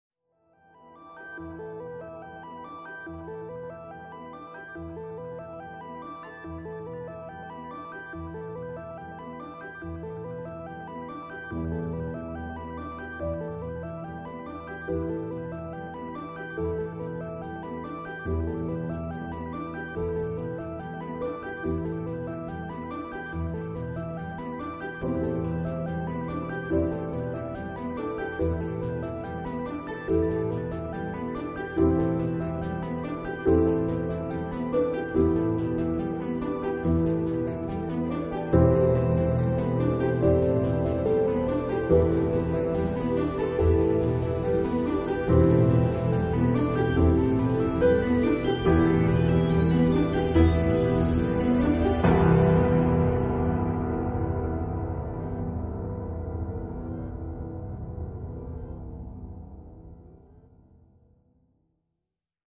Flying as a bird in sound effects free download